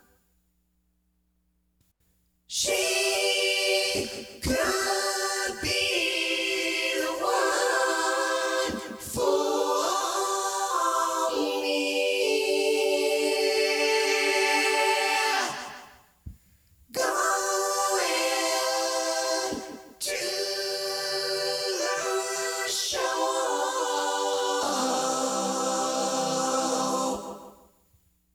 Here is a sampler type vocal from the VP9000. It causes a recognizable effect where each sung note in the run is very separated.
It is 90's tech so I sound robotic.
Every note is synthetic.
It is the bend wheel.
Attachments SampledVox.mp3 SampledVox.mp3 1.1 MB · Views: 170